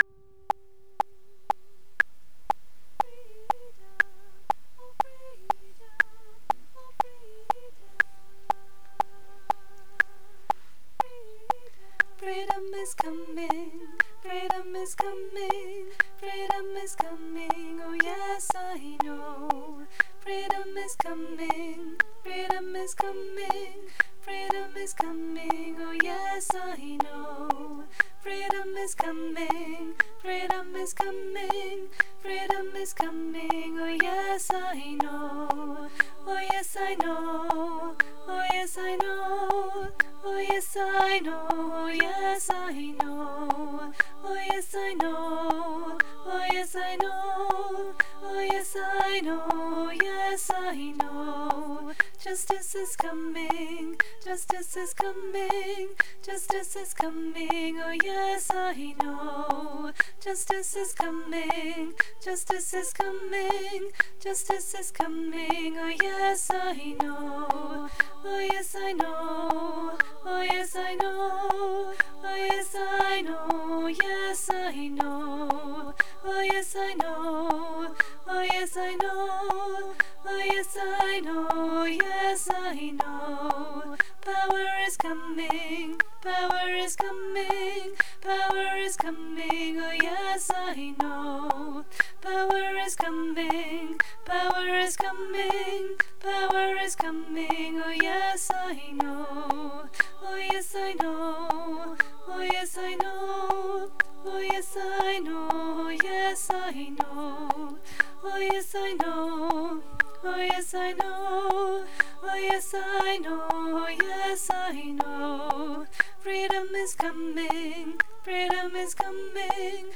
Freedom is Coming for SCF19 LOWER ALTO - Three Valleys Gospel Choir
Freedom is Coming for SCF19 LOWER ALTO